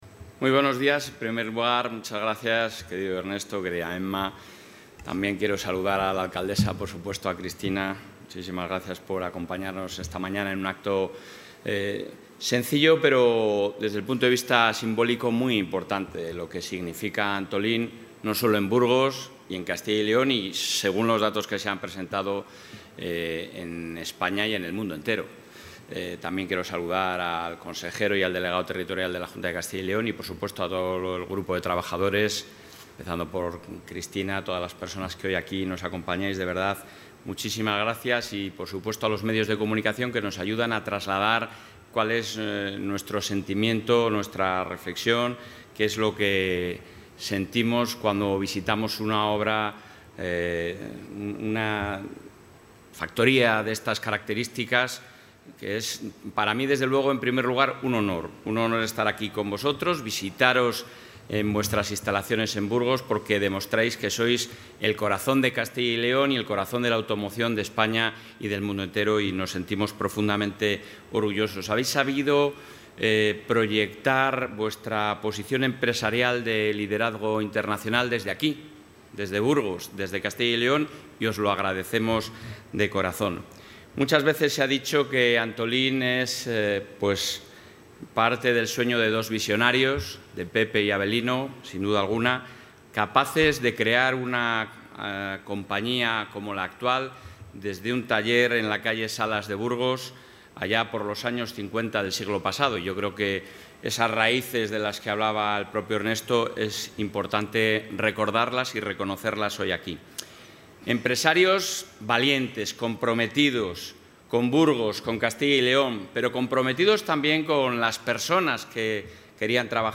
En su vista a las instalaciones de Antolin en Burgos, el presidente de la Junta de Castilla y León ha destacado el compromiso del Gobierno...
Intervención del presidente de la Junta.